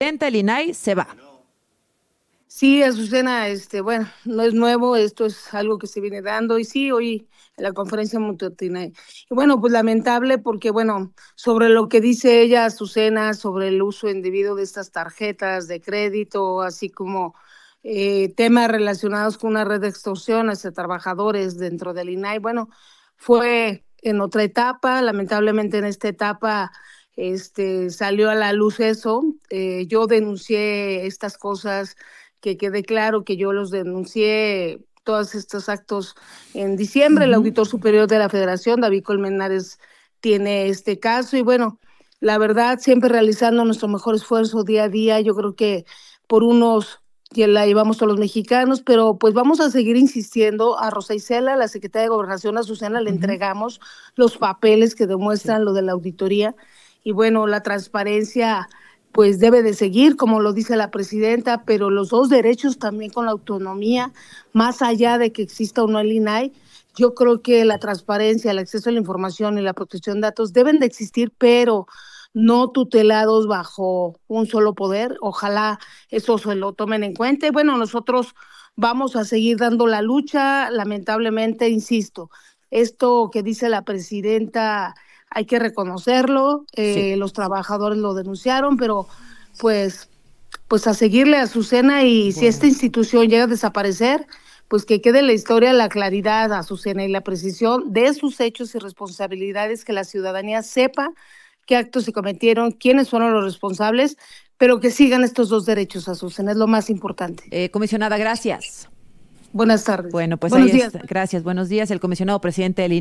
Entrevista con Azucena Uresti
Platico con Azucena Uresti en Radio Fórmula sobre las declaraciones de la Presidenta Claudia Sheinbaum